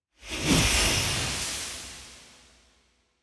UI_BoneTablet_Appear.ogg